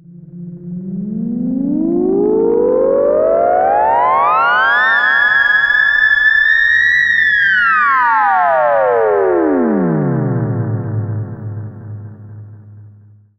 Theremin_Swoop_15.wav